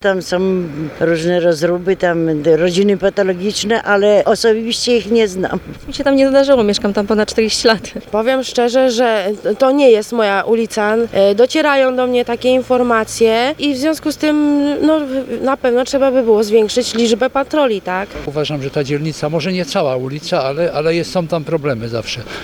– mówią stargardzianie.